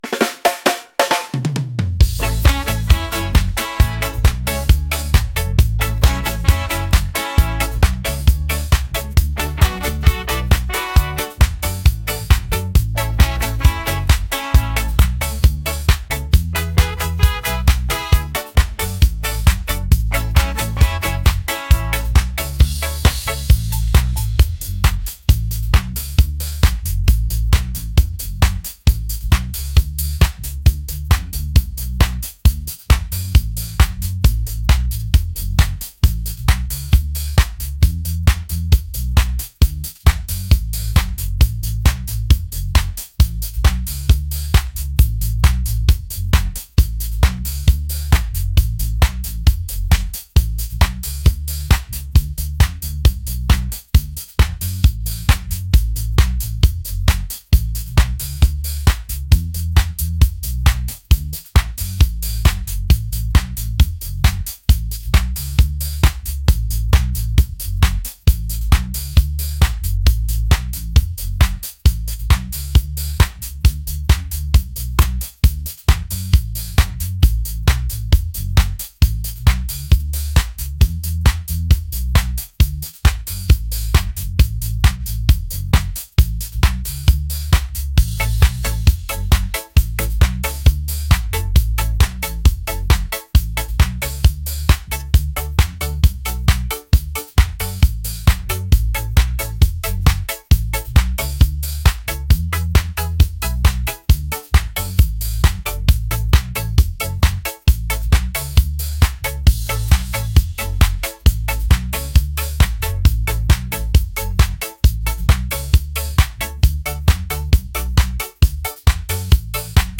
reggae | upbeat | energetic